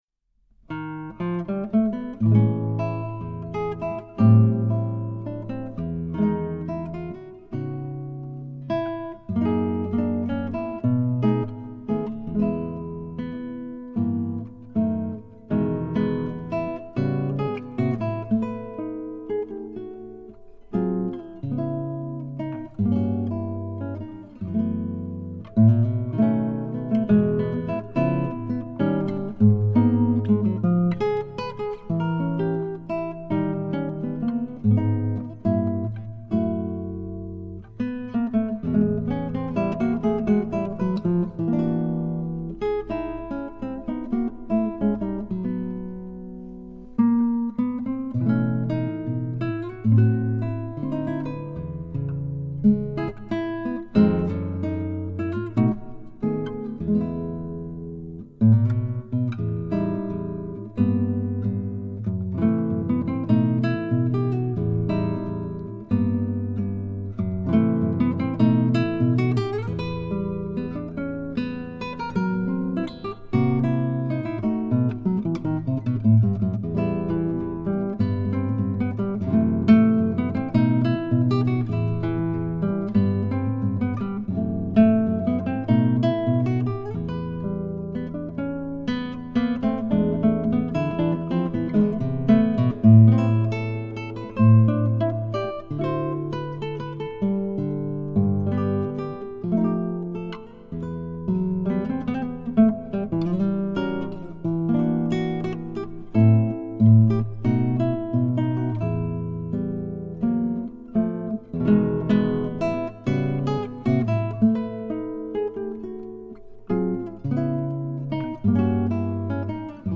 (Tangos y una Guitarra)
Guitarra y Arreglos